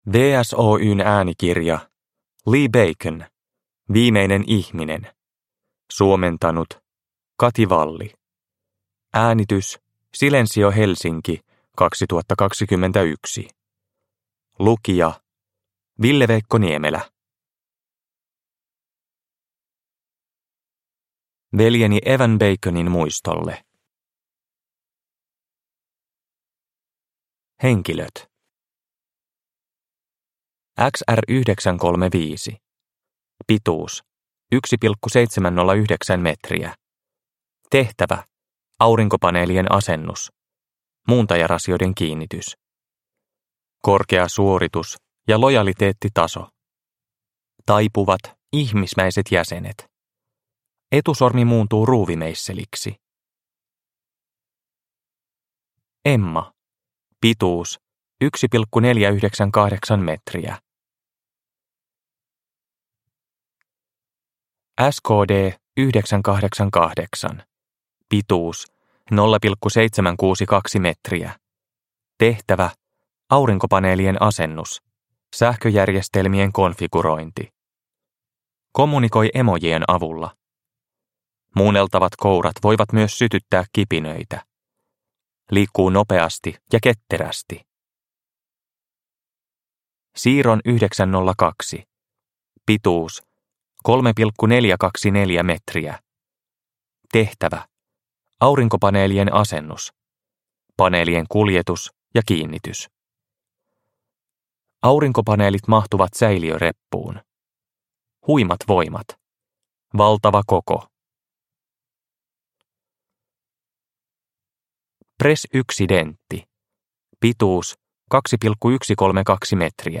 Viimeinen ihminen – Ljudbok – Laddas ner